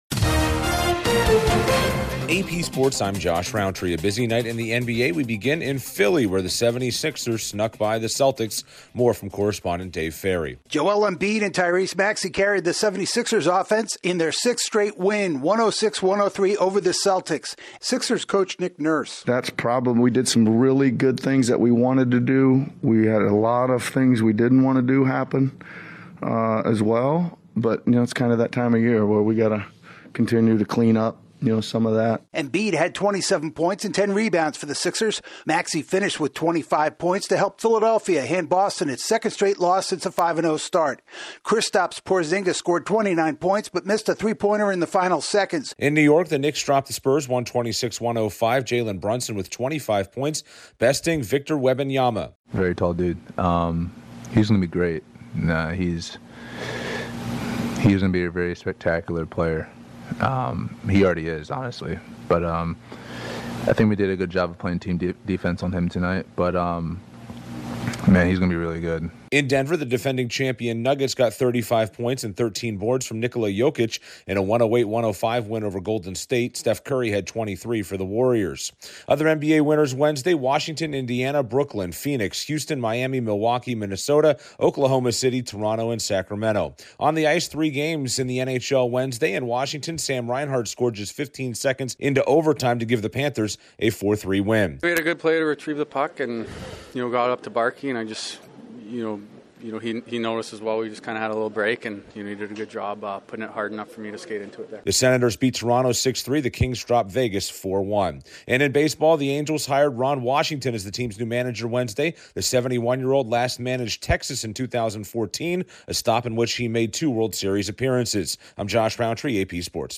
The Celtics, Knicks and Nuggets are among the NBA’s big winners, the Panthers beat the Capitals in one of the NHL’s three games and the Angels have a new manager. Correspondent